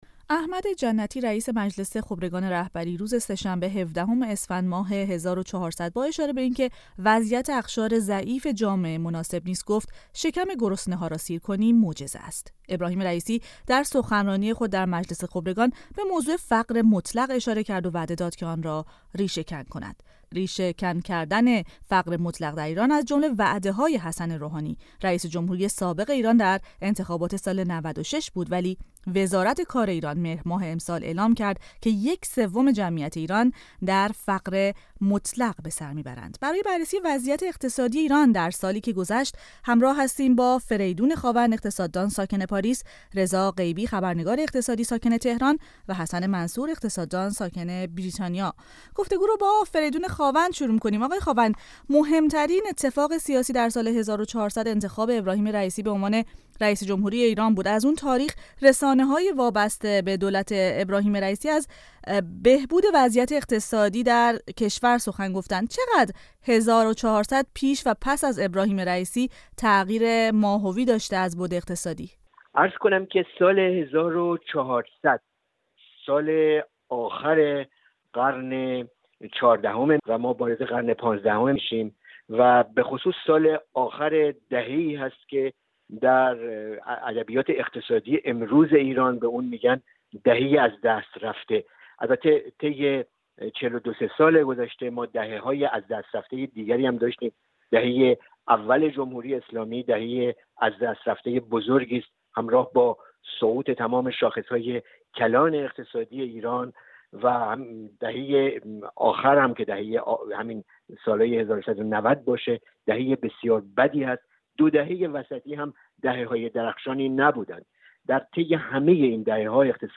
در آغاز سال ۱۴۰۱ و قرن ۱۵ هجری شمسی رادیو فردا در میزگردهایی با شرکت کارشناسان، تحلیل‌گران و فعالان عرصه‌های مختلف سیاسی، اقتصادی، فرهنگی و اجتماعی به بررسی وضعیت ایران در زمینه‌های مختلف پرداخته است.